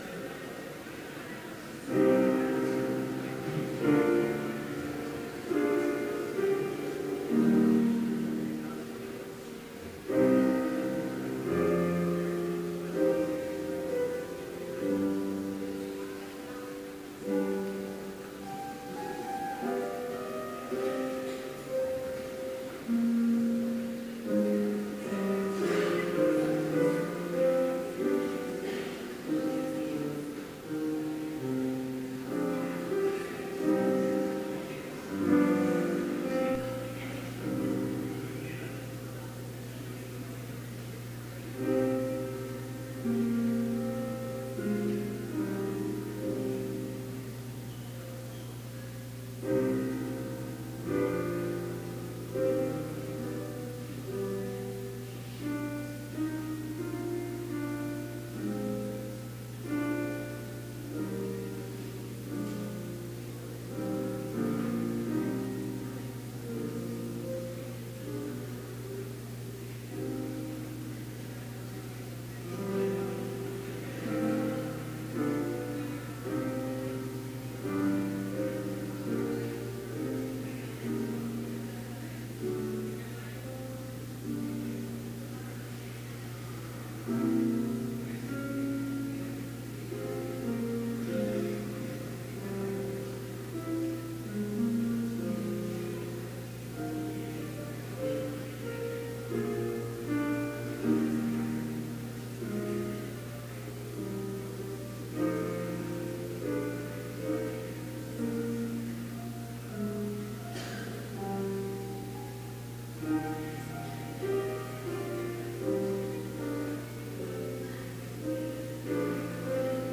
Complete service audio for Chapel - November 10, 2017